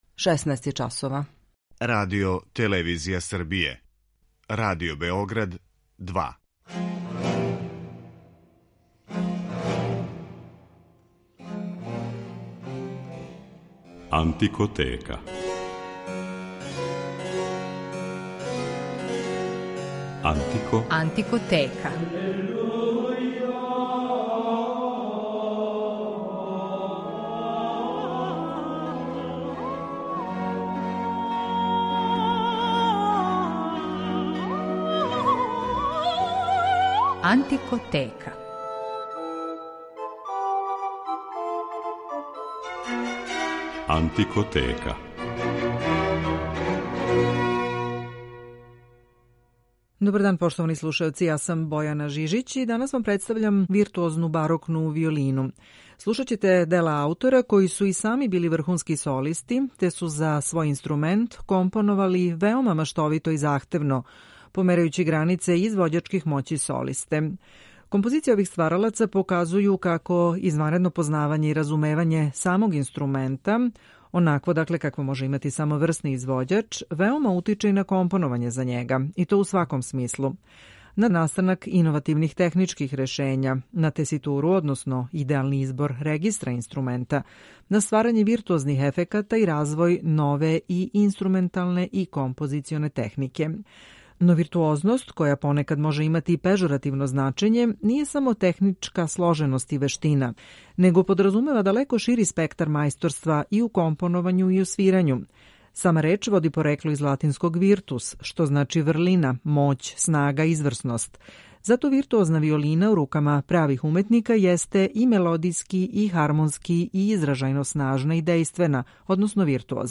Виртуозна барокна виолина
Слушаћете сонате и концерте Томаза Виталија, Арканђела Корелија, Пјетра Локателија, Антонија Вивалдија и Ђузепа Тартинија. Рубрика „Антикоскоп" посвећена је чувеној школи градитеља инструмената из Кремоне ‒ Амати.